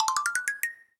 Sound effect of Dry Bone Revival in Super Mario 3D World.
SM3DW_Dry_Bone_Revive.oga.mp3